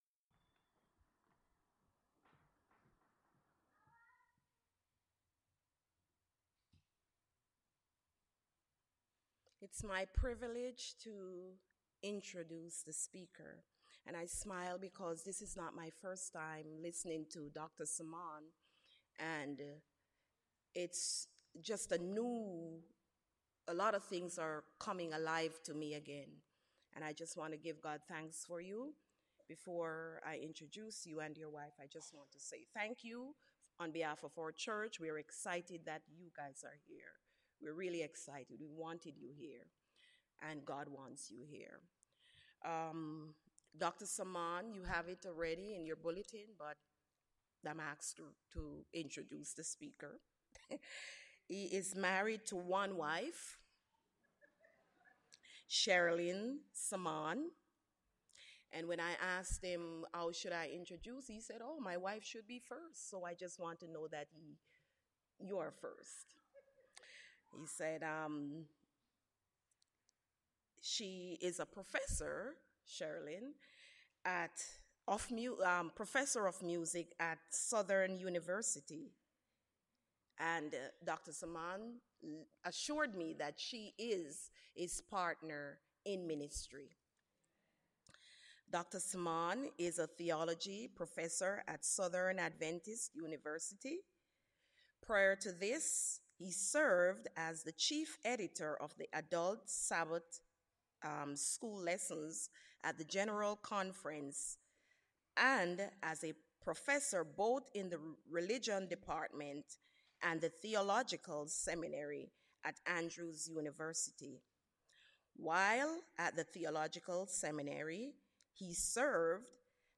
Service Type: Sabbath Worship